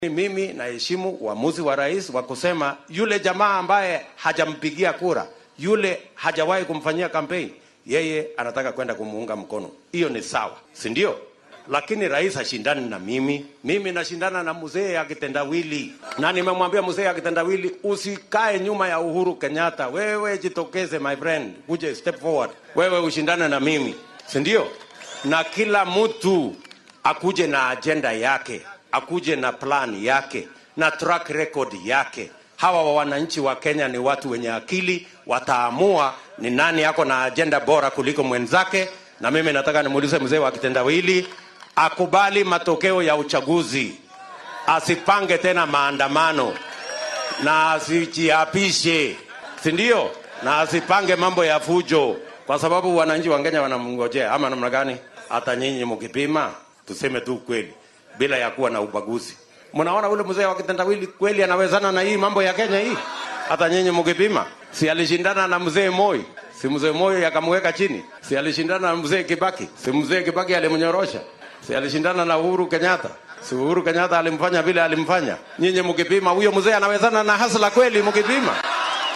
Madaxweyne ku xigeenka daka William Ruto oo ololihiisa uu ku doonaya xilka madaxtinimo ee doorashada guud bisha siddeedaad ee sanadkan maanta geeyay ismaamulka Kitui ayaa sheegay inuu ixtiraamaya go’aanka uu hoggaamiyaha ugu sarreeya dalka ku taageeray madaxa xisbiga ODM Raila Odinga.
Mar uu arrimahan ka hadlay ayuu yiri ku xigeenka madaxweynaha dalka